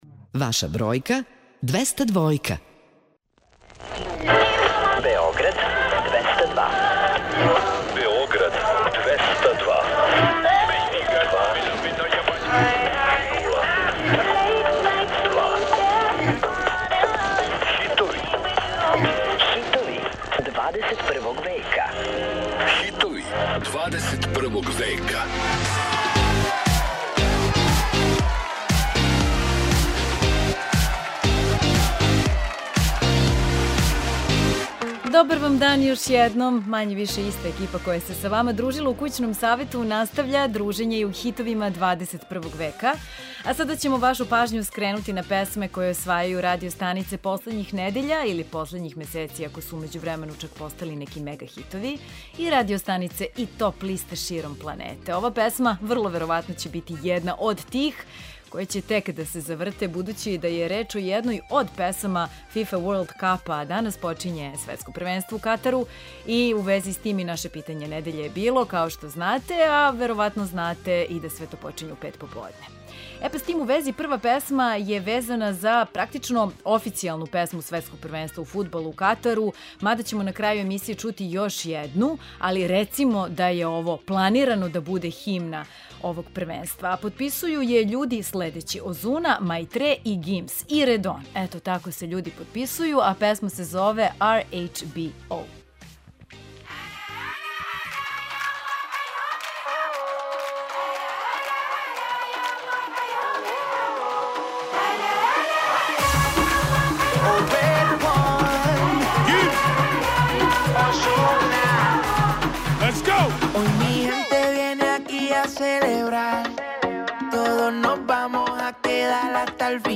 Čućete pesme koje se nalaze na vrhovima svetskih top lista.